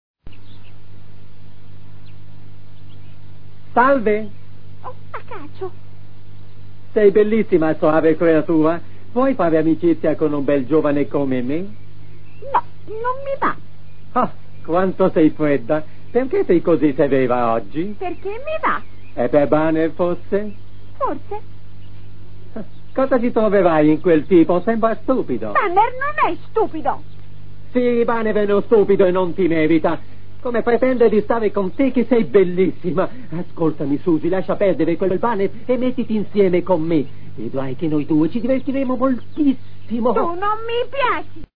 nel cartone animato "Lo scoiattolo Banner", in cui doppia Acacio.